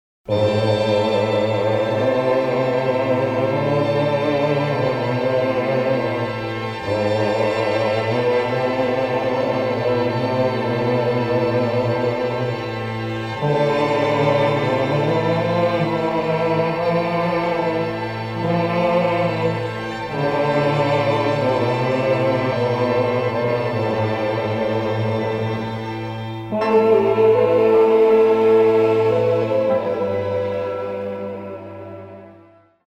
A big, powerful, deeply emotional beautiful score.